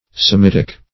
Semitic \Sem*it"ic\, a.
semitic.mp3